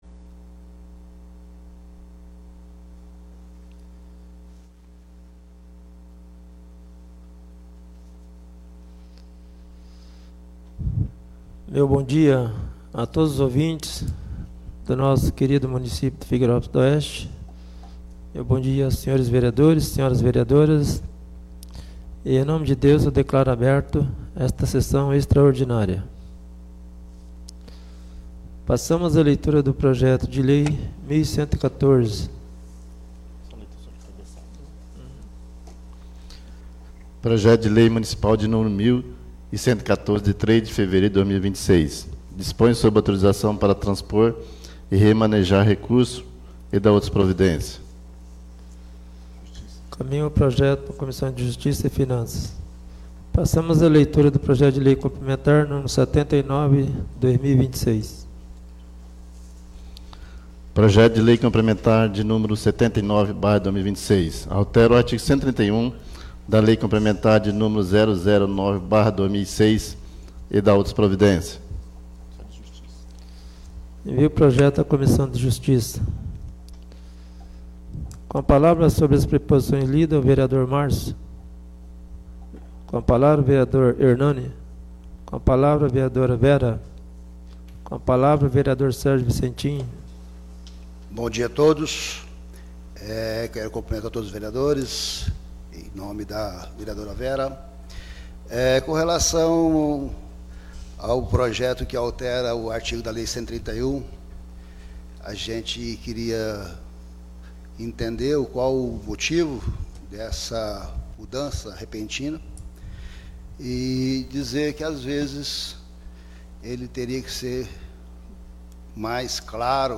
2° SESSÃO EXTRAORDINÁRIA DE 05 DE FEVEREIRO DE 2026